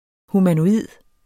Udtale [ humanoˈiˀð ]